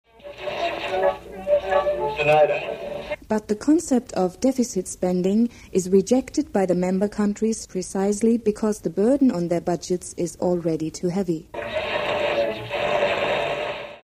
Sprecherin deutsch. Warme, vielseitige Stimme, Schwerpunkte: Lesungen, musikalische Lesungen, Rezitationen,
Sprechprobe: eLearning (Muttersprache):
Female voice over artist German